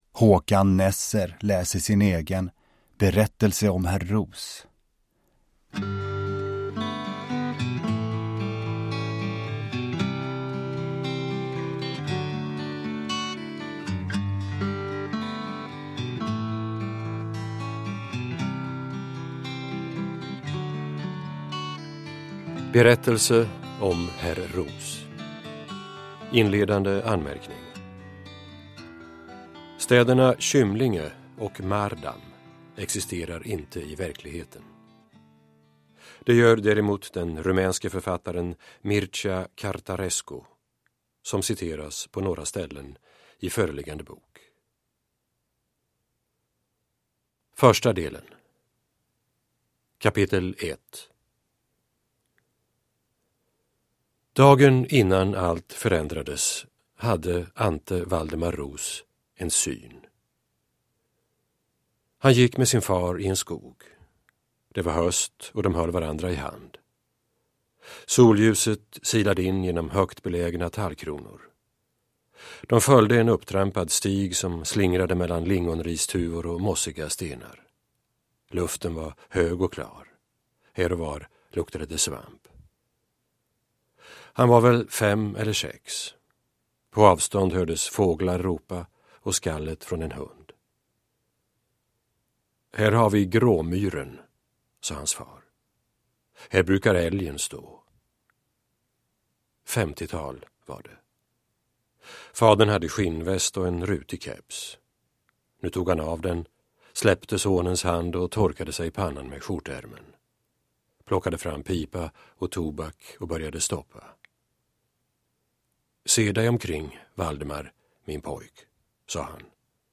Uppläsare: Håkan Nesser
Ljudbok